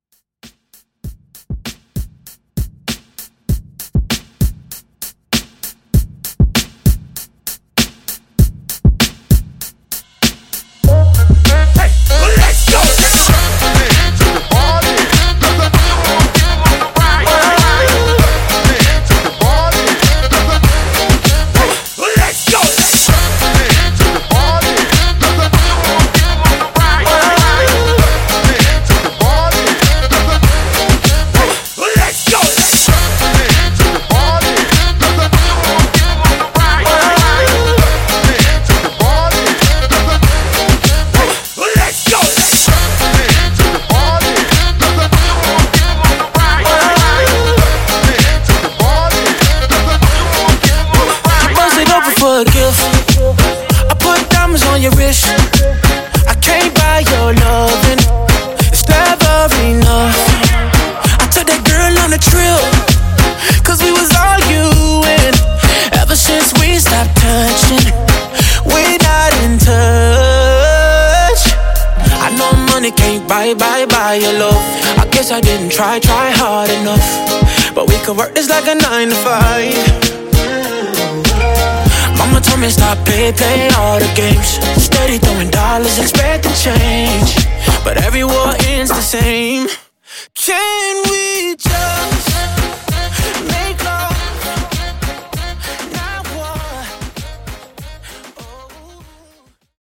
Moombah Party Starter)Date Added